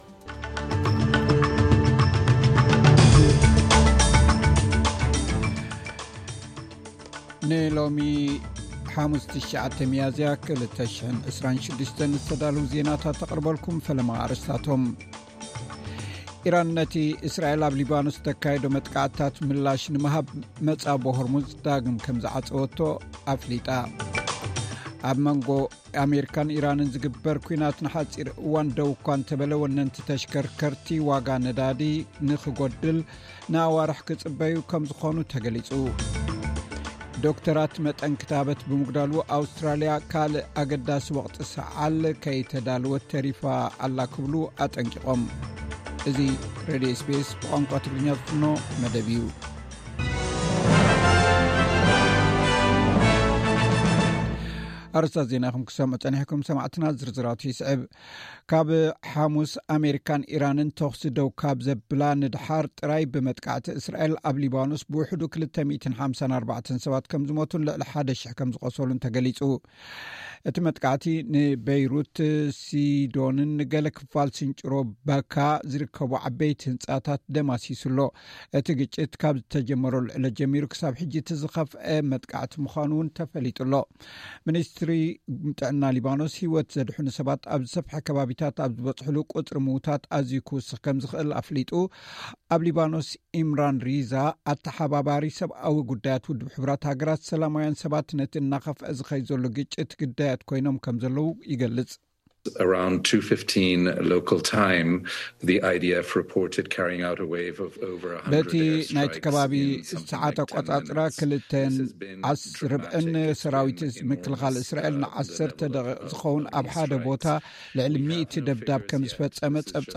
ዕለታዊ ዜና SBS ትግርኛ (09 ሚያዝያ 2026)